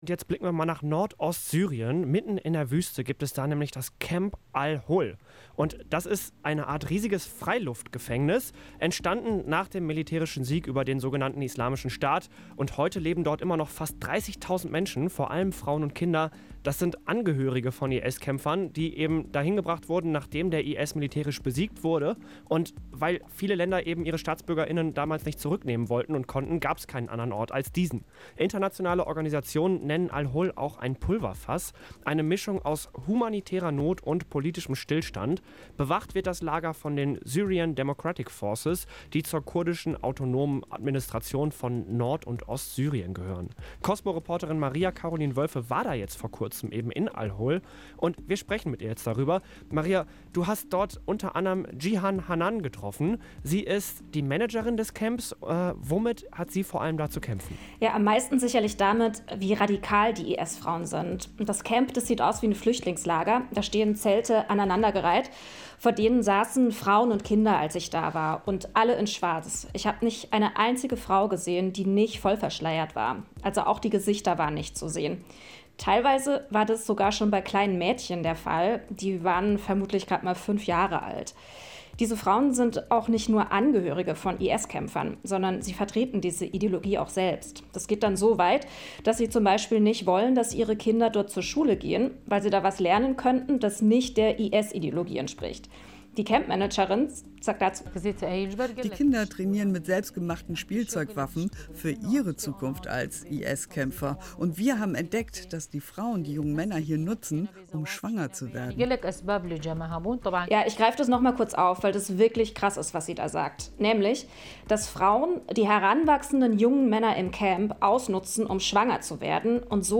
Live-Gespräch über Al Hol in Syrien ( Radio COSMO